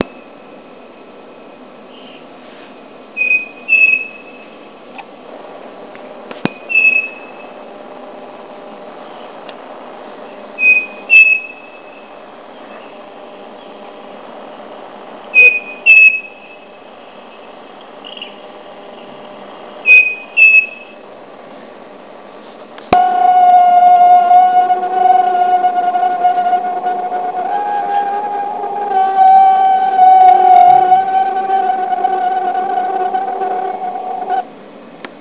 La Baleine à bosse
baleine.wav